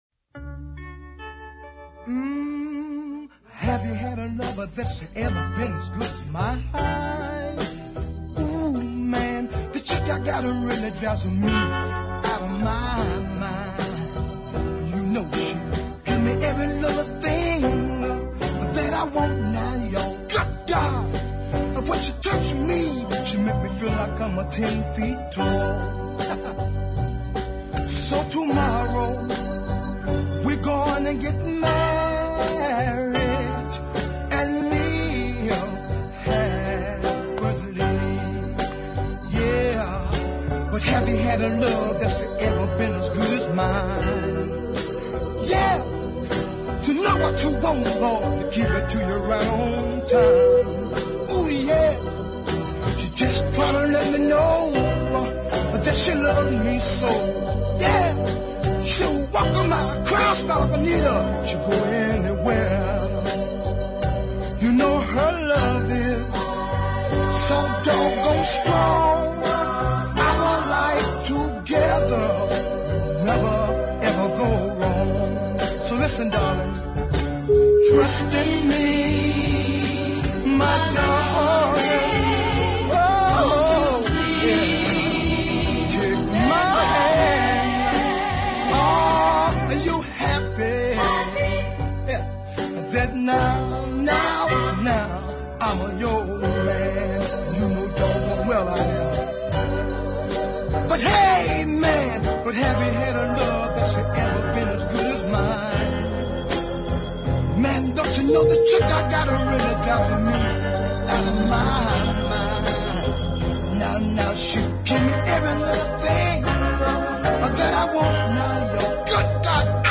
is taken at a slightly slower pace